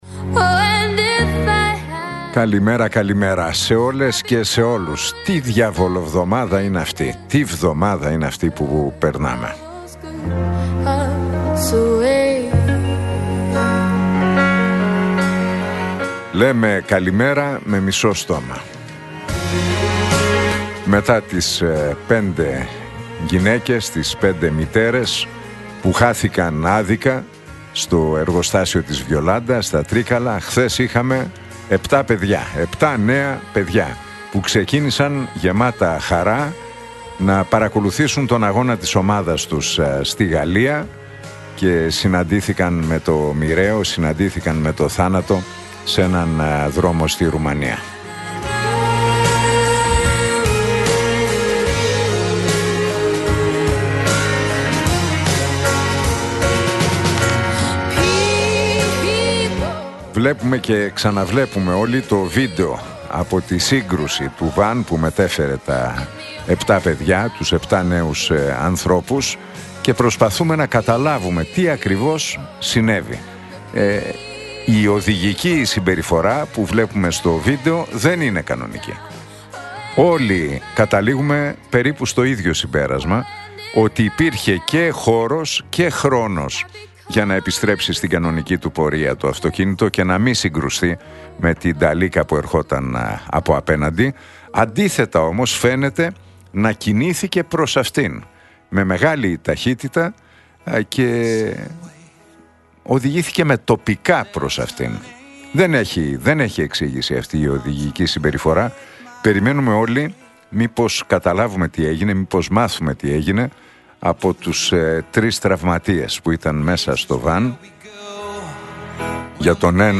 Ακούστε το σχόλιο του Νίκου Χατζηνικολάου στον ραδιοφωνικό σταθμό Realfm 97,8, την Τετάρτη 28 Ιανουαρίου 2026.